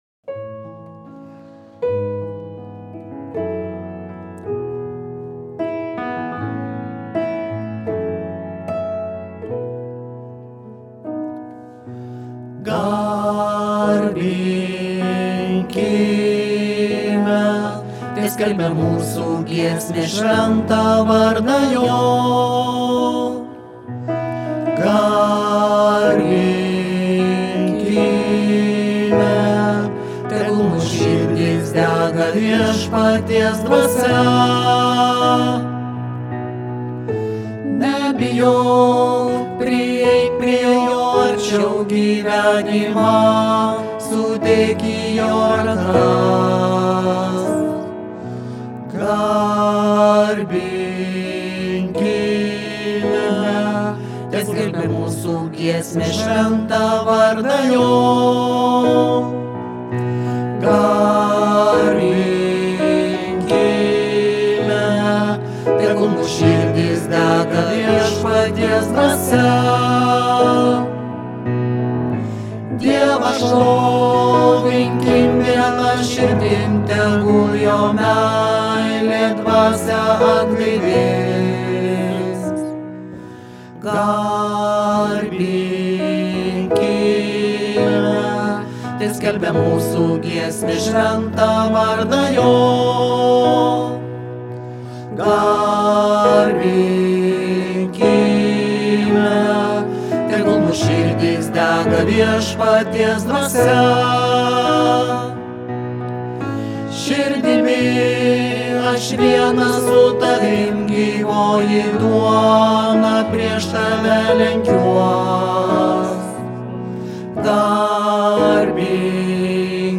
Tenoras: